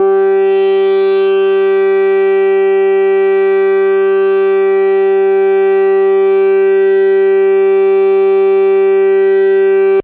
标签： FSharp4 MIDI音符-67 Arturia-Microbrute 合成 单注 多重采样
声道立体声